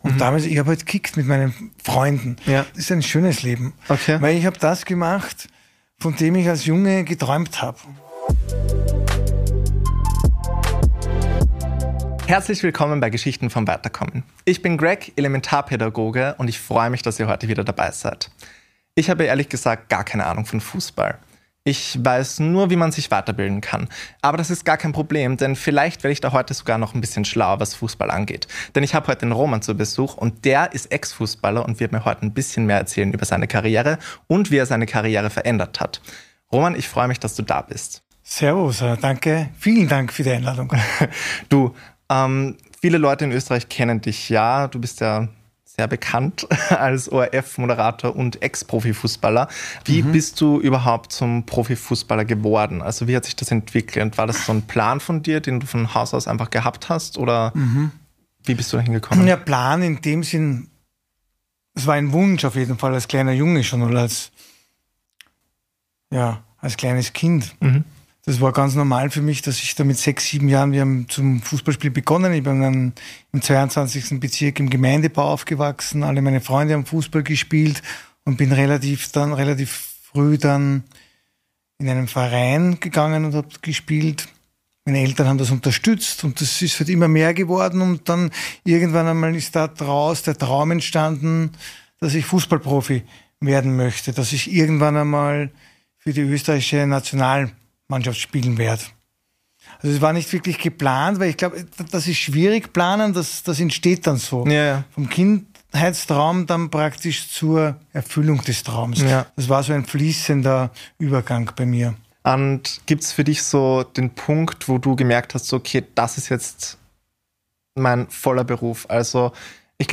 Vom Spielfeld zum Studio ~ Geschichten vom Weiterkommen – Wiener Gespräche über berufliche Veränderung Podcast